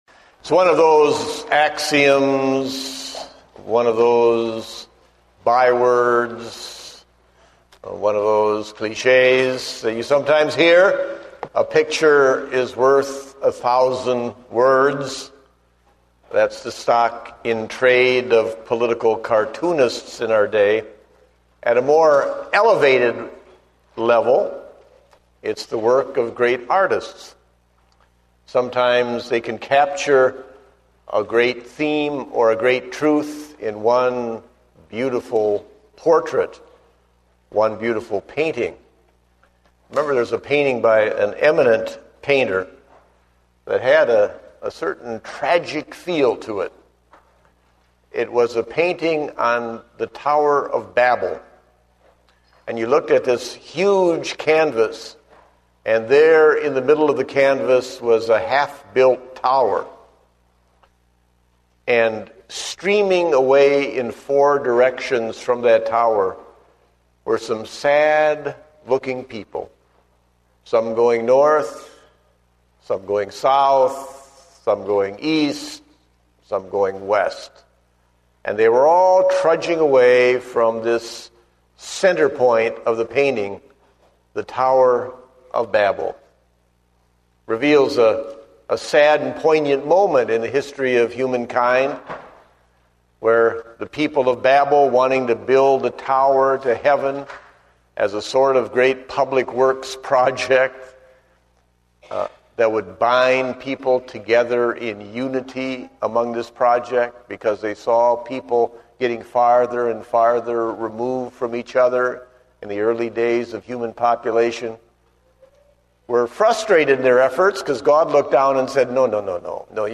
Date: August 8, 2010 (Morning Service)